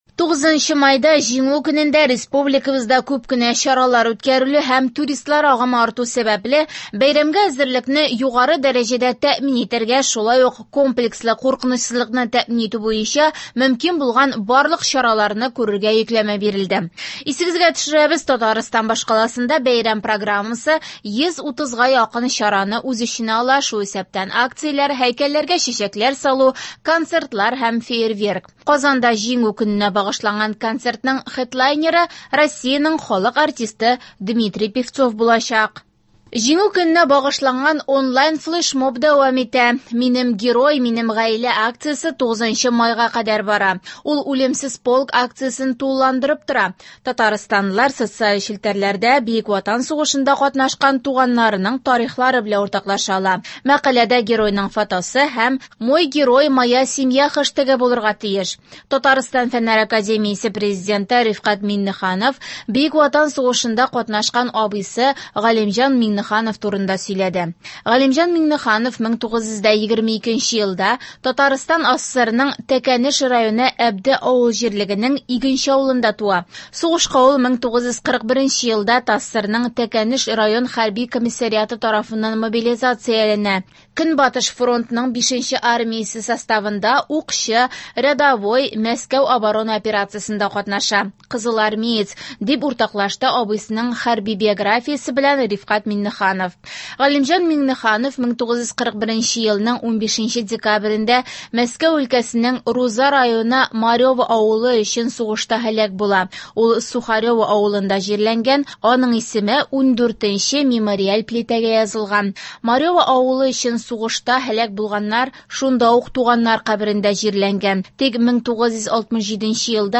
Яңалыклар (02.05.24)